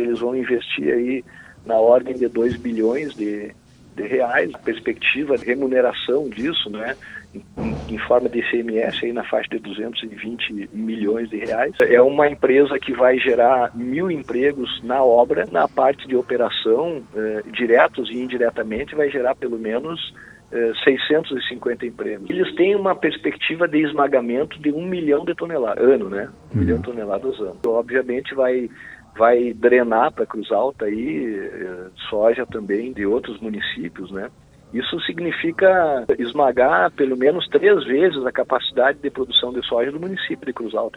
Abaixo, explicações do secretário Rogério sobre o investimento: